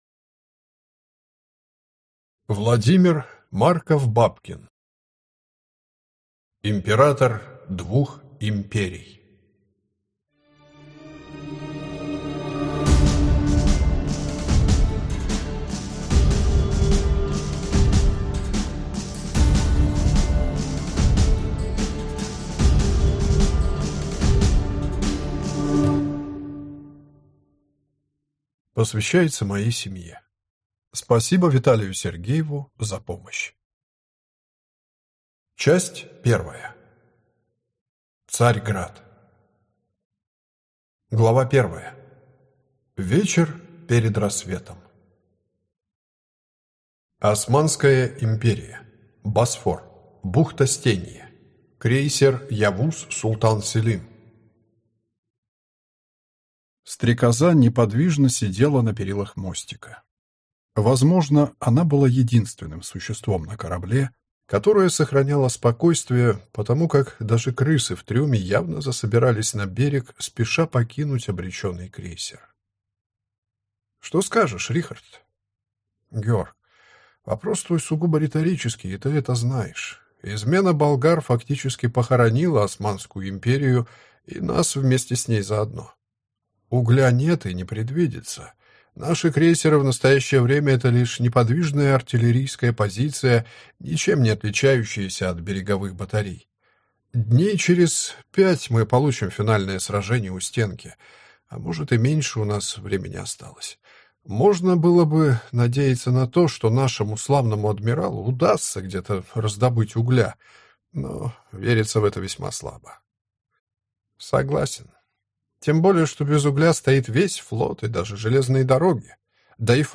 Аудиокнига Император двух Империй | Библиотека аудиокниг